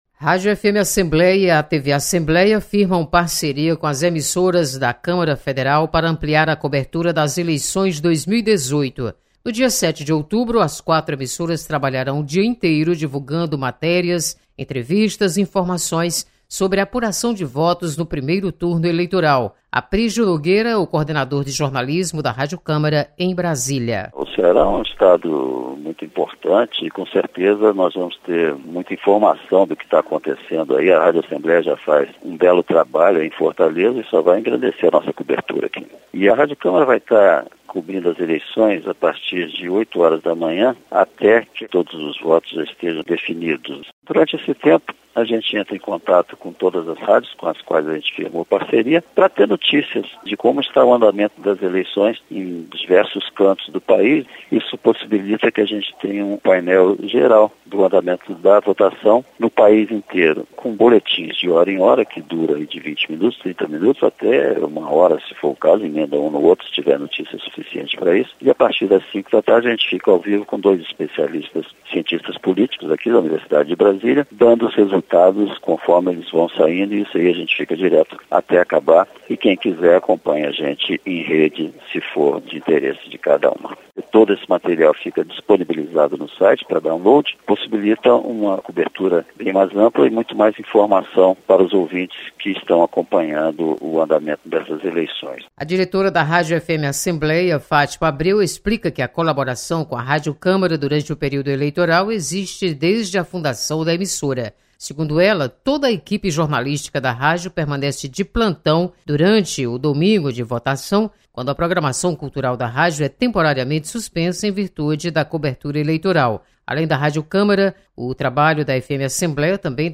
Rádio e TV Câmara firmam parceria com FM e TV Assembleia. Repórter